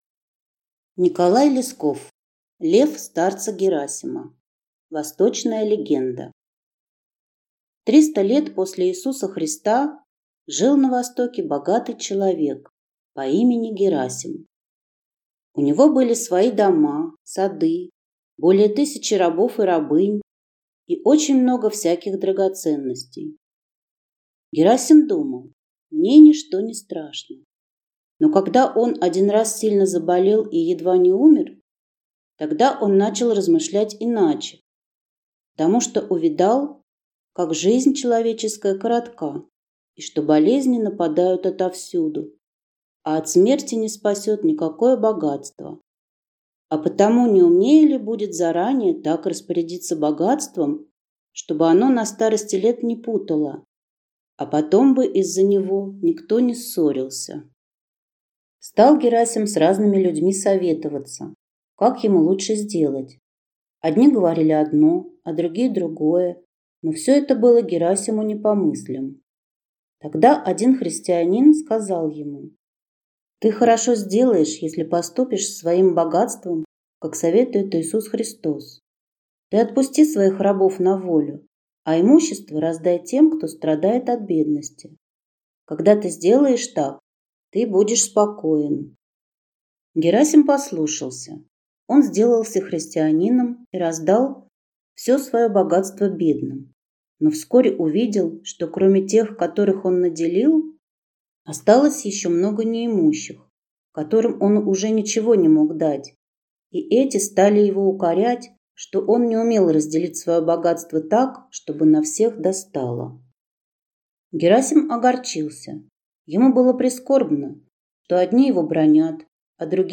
Аудиокнига Лев старца Герасима | Библиотека аудиокниг